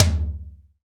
Index of /90_sSampleCDs/Roland - Rhythm Section/TOM_Real Toms 1/TOM_Dry Toms 1
TOM ATTAK 04.wav